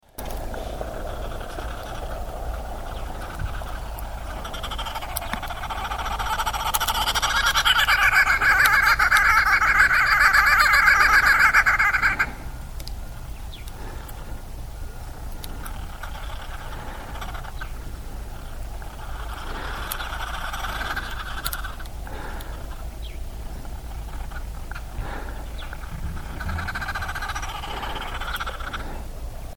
Звуци на птици - лилав кълвач (Phoeniculus damarensis)
На тази страница можете да слушате аудио звуци на птици - лилав кълвач (phoeniculus damarensis).